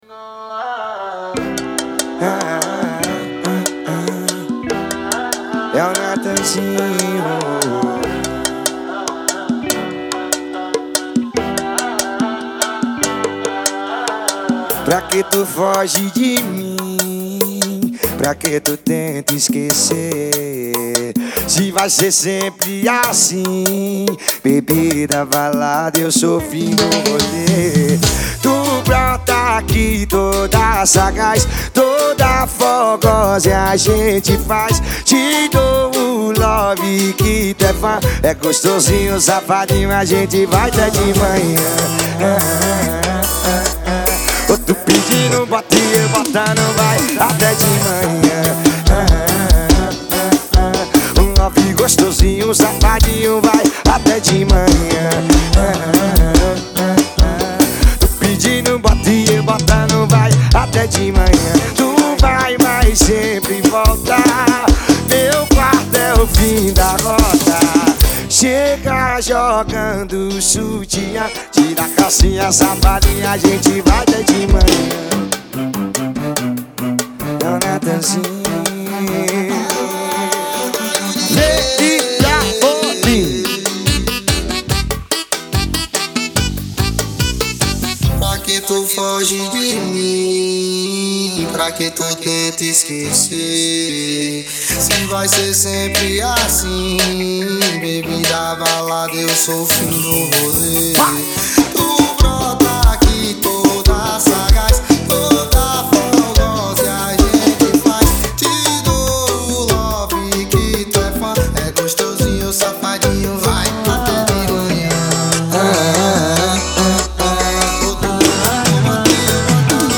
2024-04-10 19:19:17 Gênero: FORRO Views